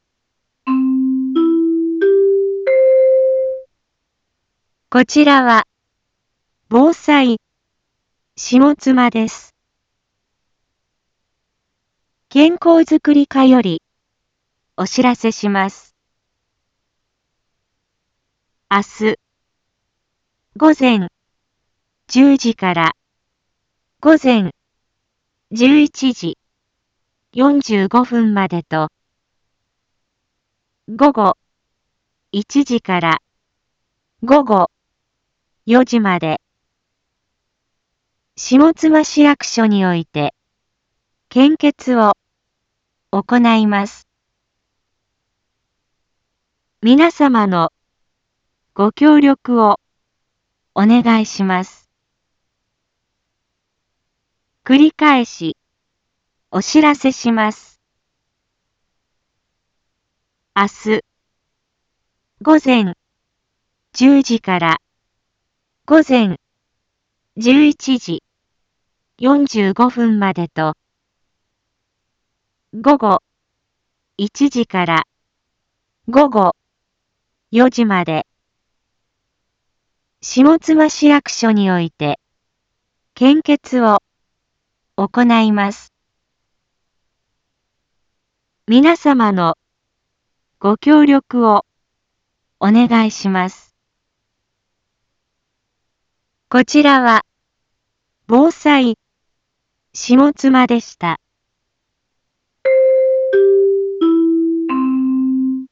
一般放送情報
Back Home 一般放送情報 音声放送 再生 一般放送情報 登録日時：2025-03-12 18:32:05 タイトル：献血のお知らせ（前日） インフォメーション：こちらは、ぼうさいしもつまです。